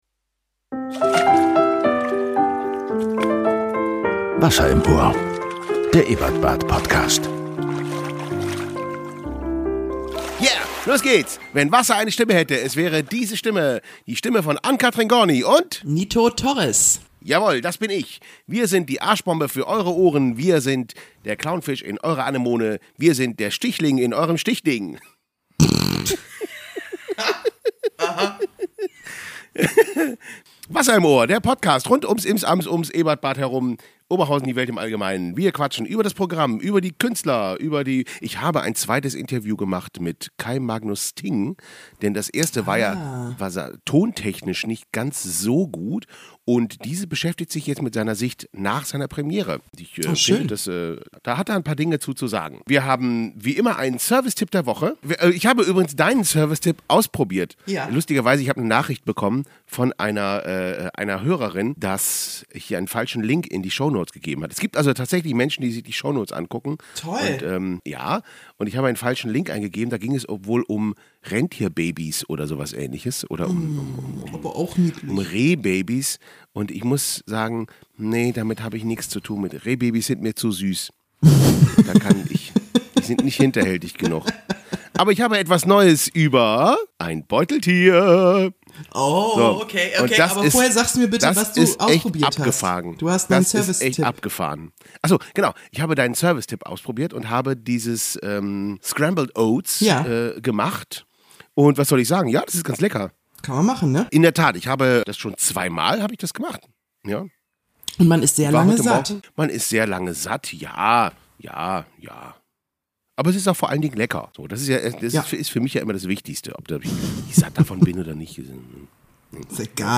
Im Interview: Kai Magnus Sting (Nach seiner der Premiere am 05.04.24 im Ebertbad)